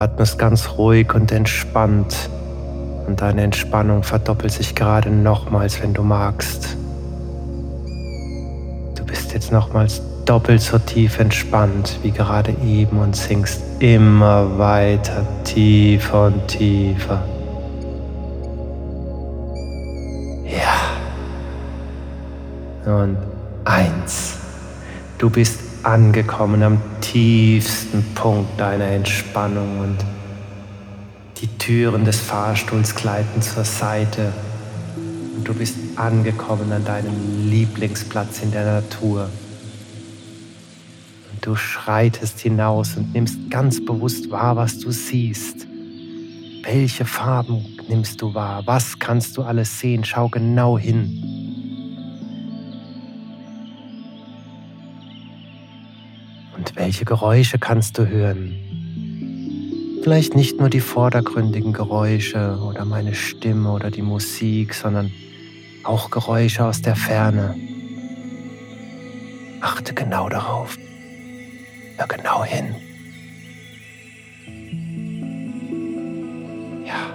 Audio-Hypnosen
mit ausgewählter und stimmungsvoller Musik untermalt